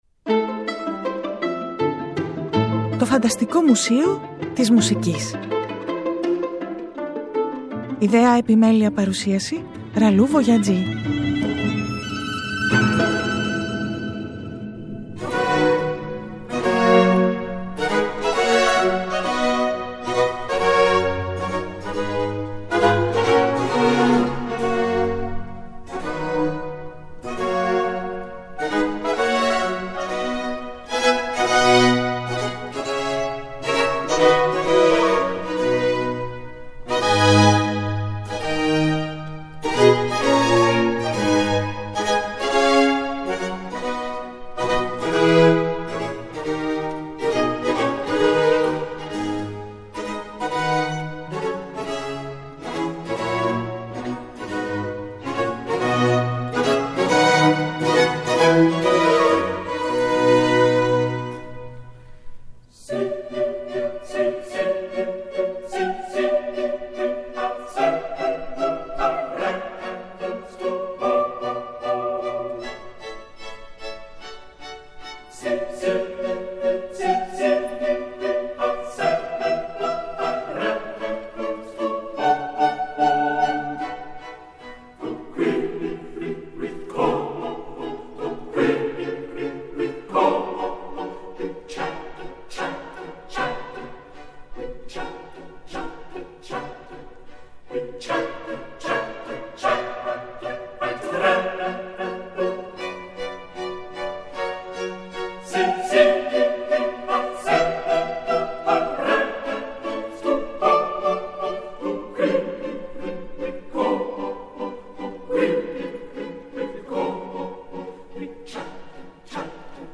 Ένα Χορωδιακό που τρέμει και τουρτουρίζει, ένα Μπαλέτο που χορεύει παραλλαγές του χιονιού και του πάγου, μία Συμφωνία για την ιστορία αναζήτησης νέων κόσμων στην πιο ψυχρή γη∙ ξενάγηση με βήματα στο χιόνι σε μουσική του ψύχους.